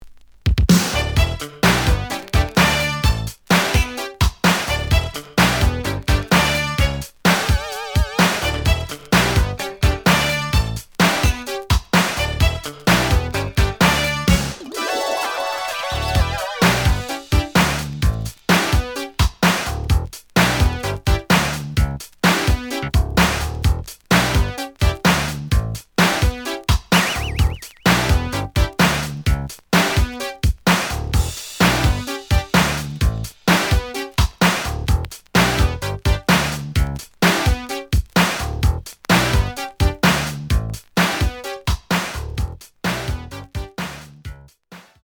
(Instrumental)
試聴は実際のレコードから録音しています。
●Genre: Disco